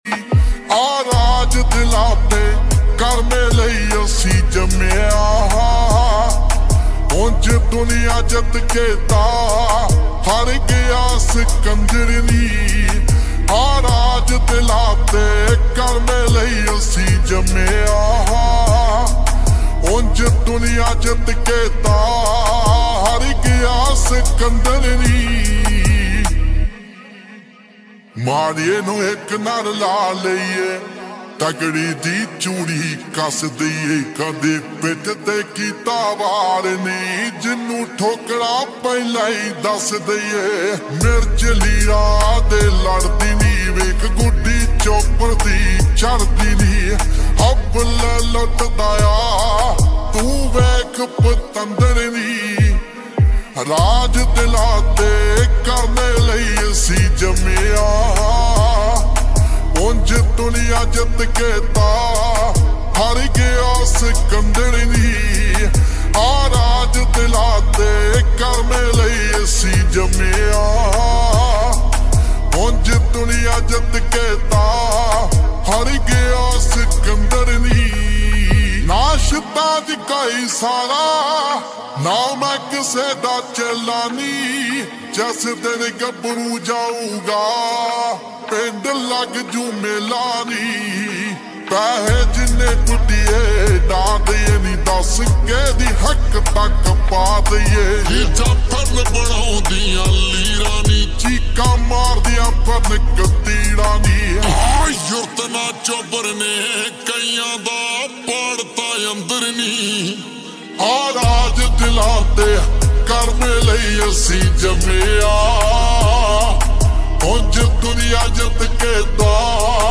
Full Song Slowed And Reverb